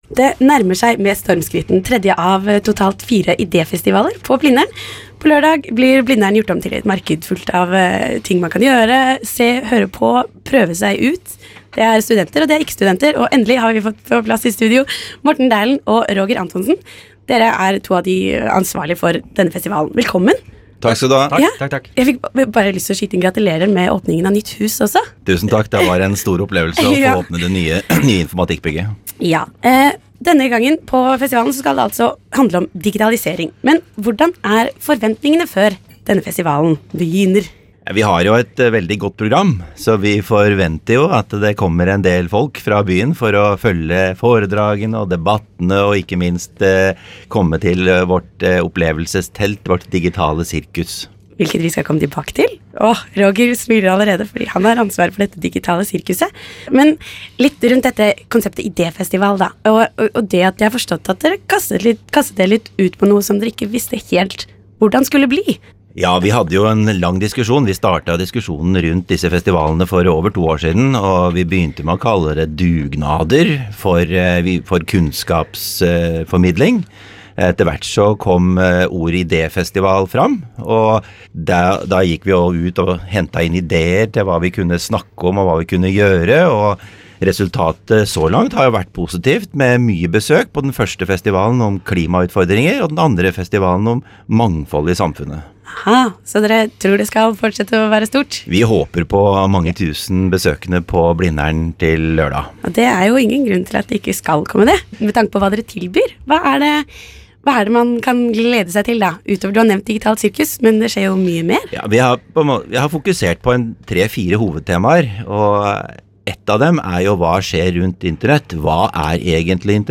Intervju om UiO-festivalen